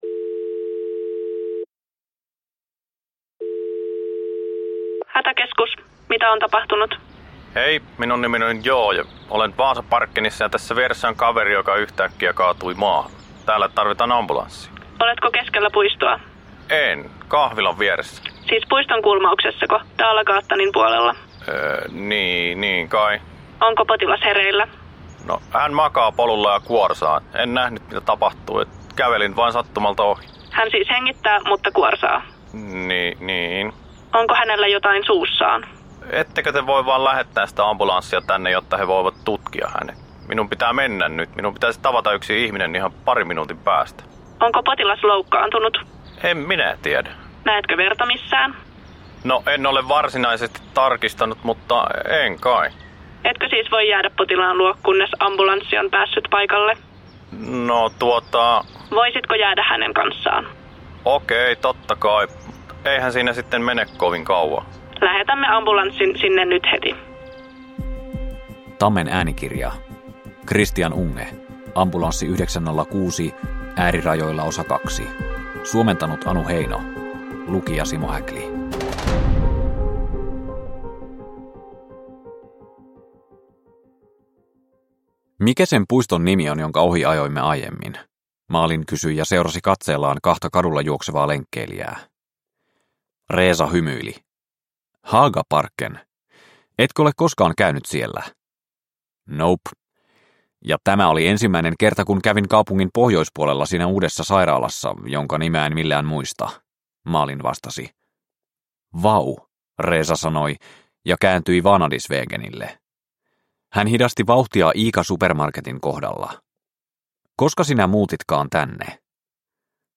Ambulanssi 906 Osa 2 – Ljudbok – Laddas ner